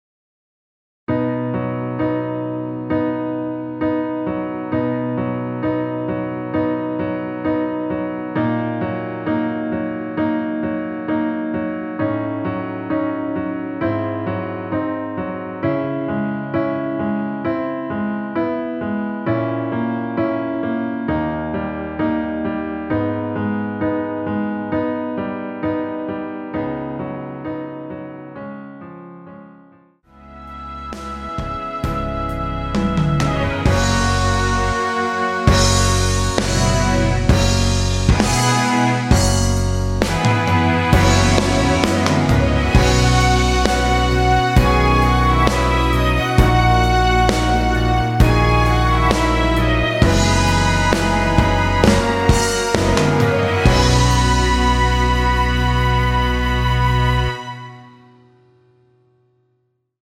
전주 없이 시작하는 곡이라 전주 1마디 만들어 놓았으며
원키에서(-1)내린 MR입니다.
앞부분30초, 뒷부분30초씩 편집해서 올려 드리고 있습니다.